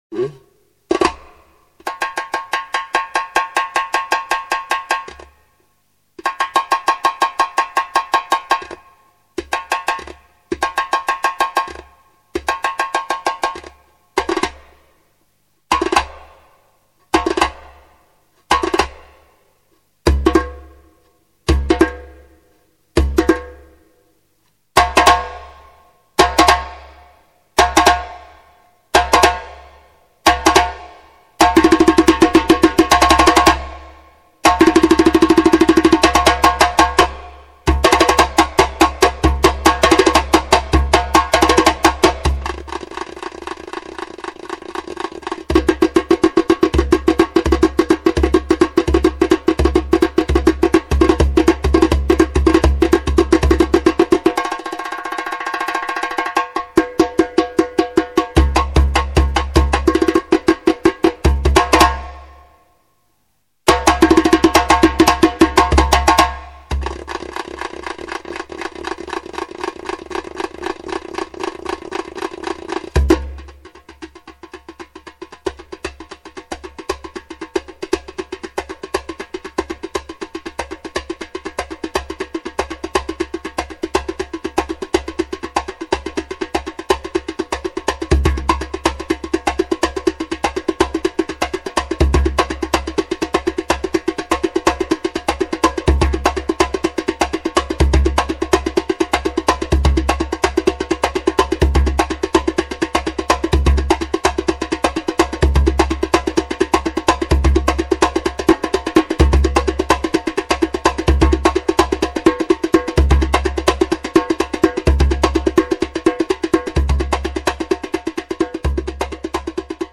djembe_solo.mp3